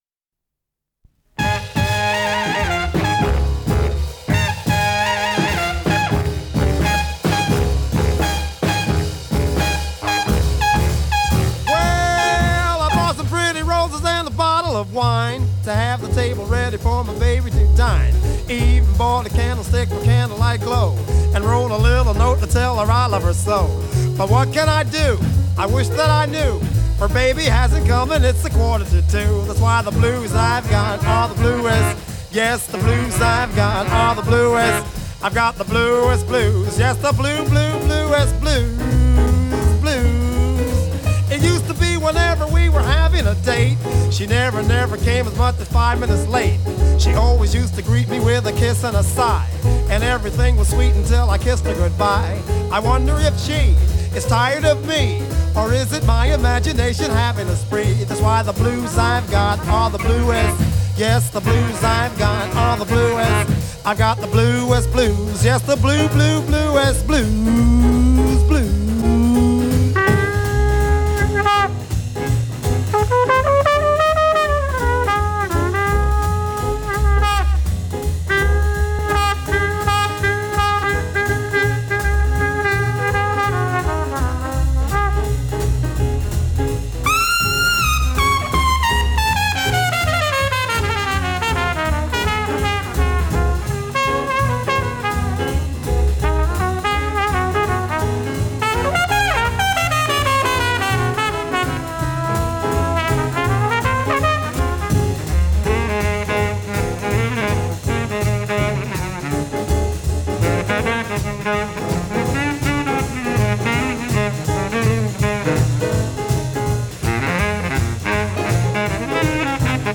с профессиональной магнитной ленты
вокал
АккомпаниментИнструментальный ансамбль
Скорость ленты38 см/с